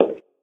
inside-step-4.ogg